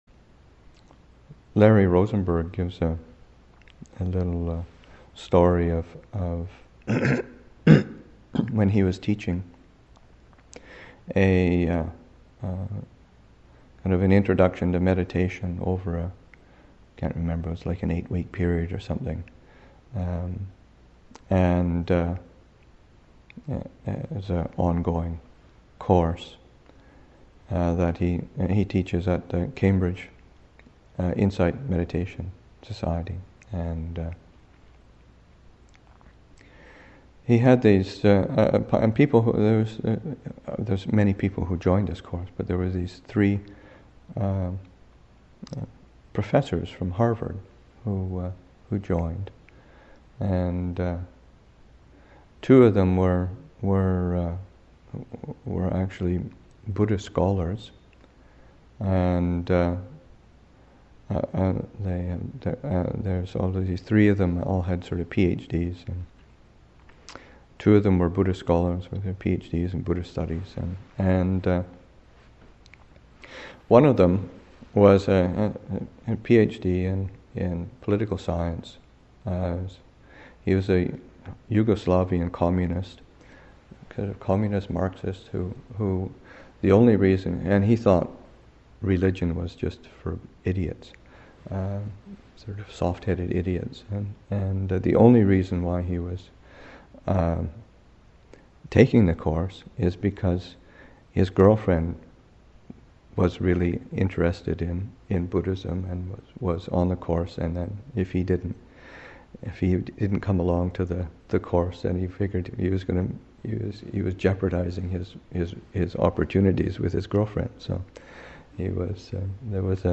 Story: Three Harvard professors take a course in meditation.